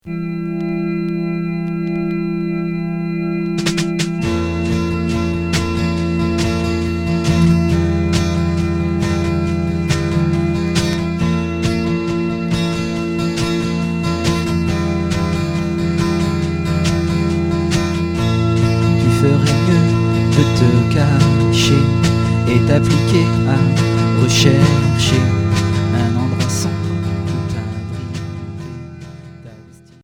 Noisy pop Deuxième 45t